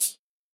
UHH_ElectroHatB_Hit-34.wav